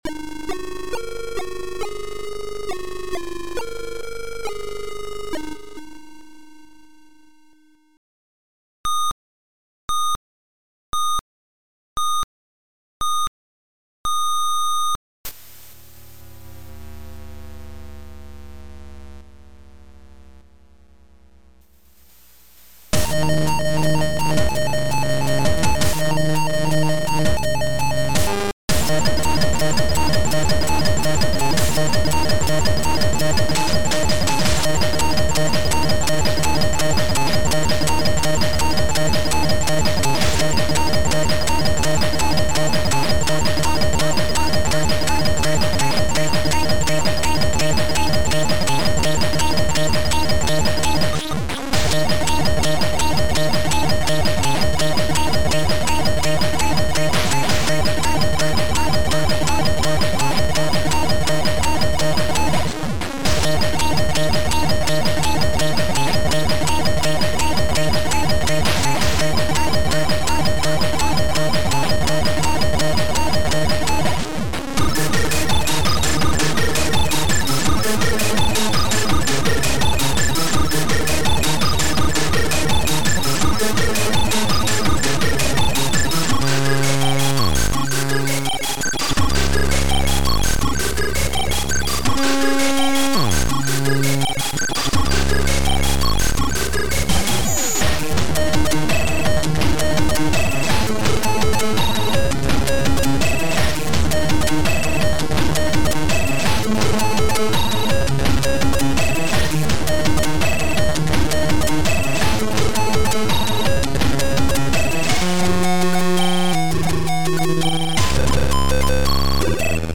• An allocated channels for playing by default is ABC
• Sound chip AY-3-8912 / YM2149